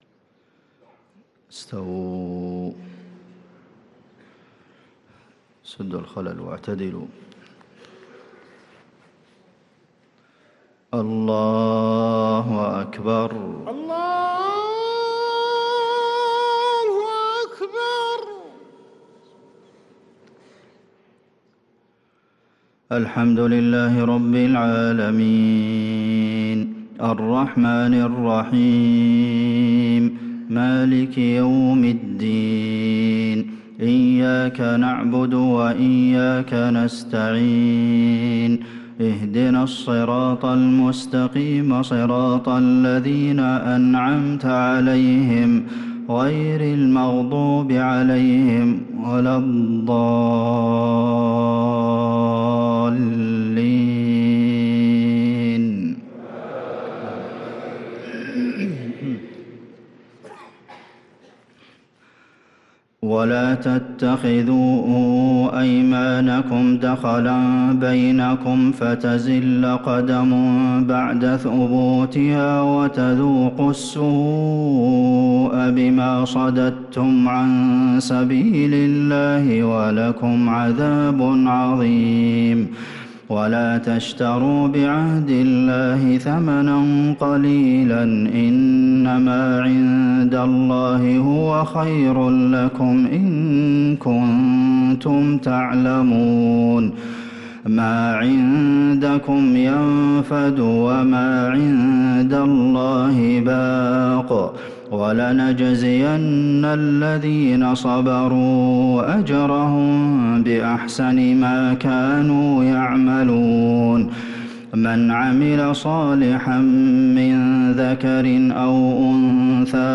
صلاة العشاء للقارئ عبدالمحسن القاسم 20 رجب 1445 هـ
تِلَاوَات الْحَرَمَيْن .